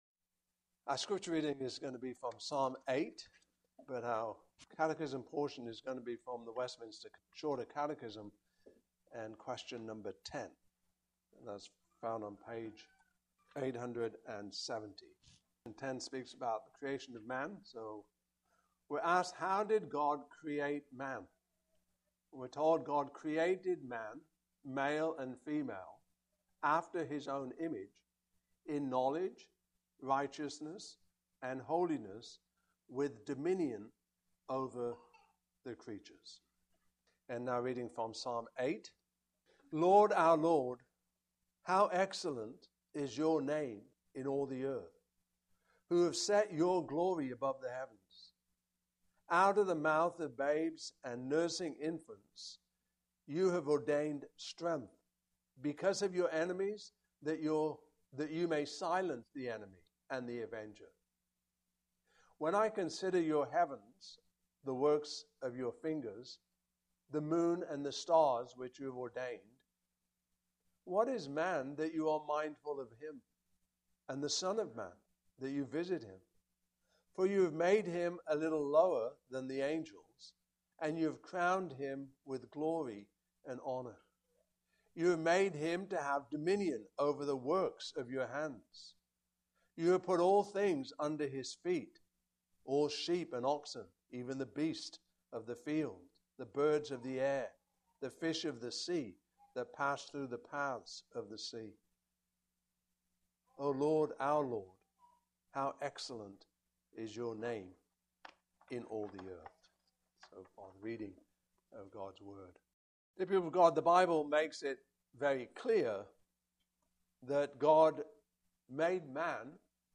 Westminster Shorter Catechism Passage: Psalm 8:1-9 Service Type: Evening Service Topics